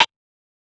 SNARE BLOCK .wav